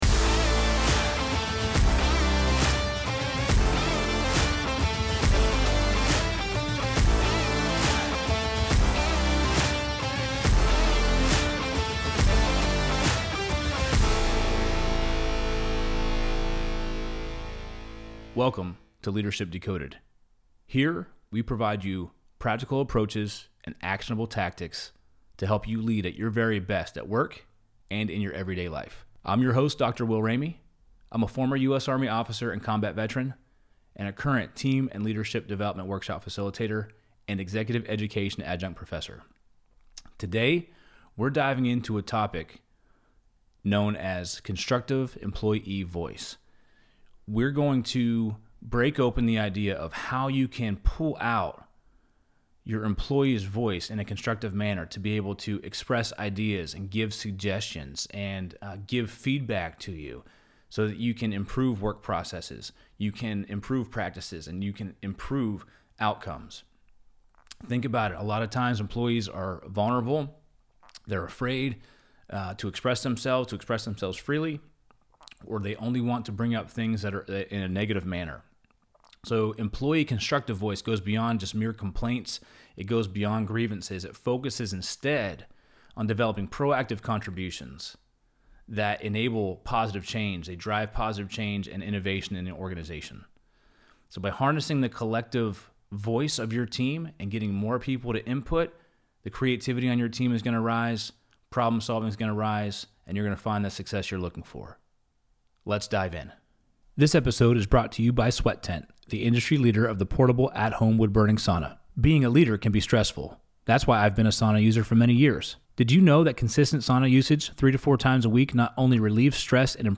Welcome to Ep.027 of the Leadership Decoded Podcast in the Loop Internet studio